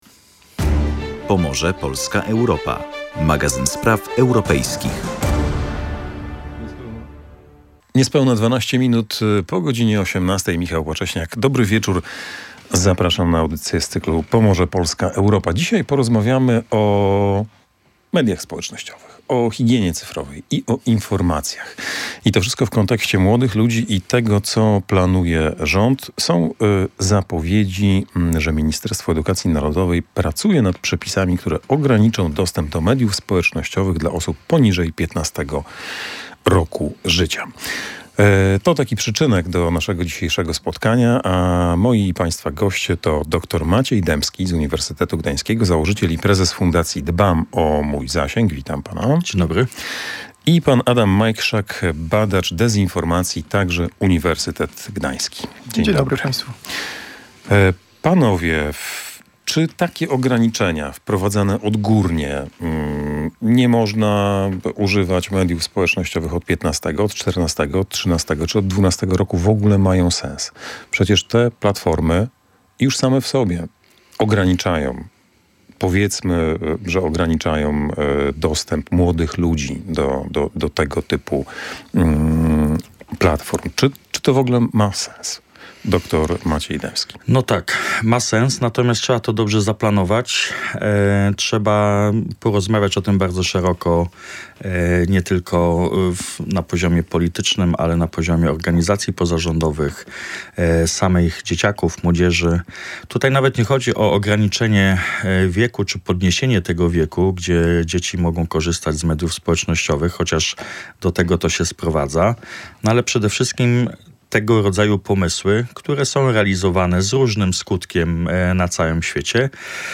Z jak dużą skalą dezinformacji się w nich borykamy i kto jest na nią podatny? Między innymi o tym rozmawialiśmy w audycji „Pomorze, Polska, Europa”.